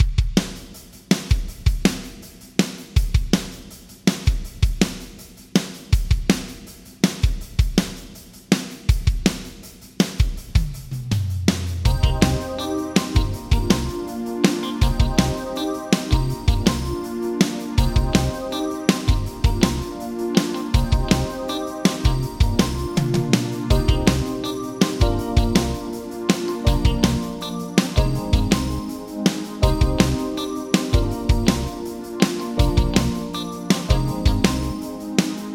Drums-Yamaha-ProR3-LA-Plate-Long-Vintage-Digital.mp3